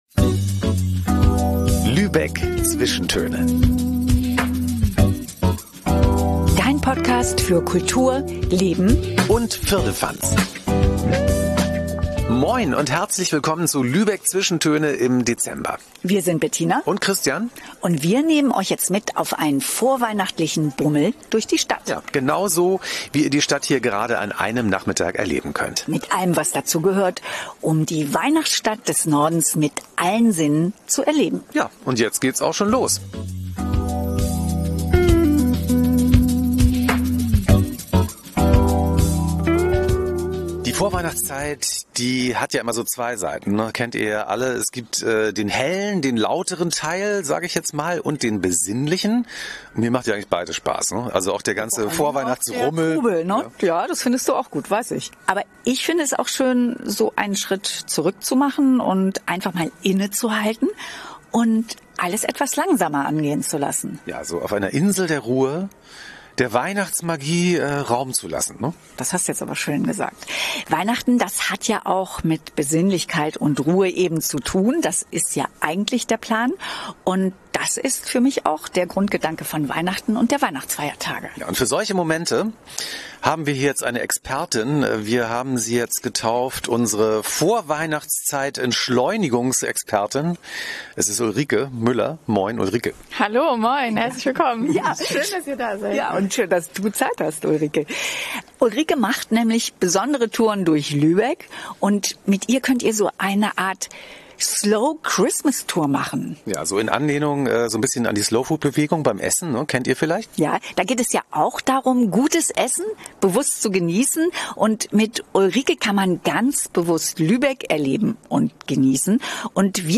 Wir nehmen dich mit auf einen akustischen Bummel durch das sehr vorweihnachtliche Lübeck – durch die lebendigen Weihnachtswelten und die festlich stillen Gassen und Hinter...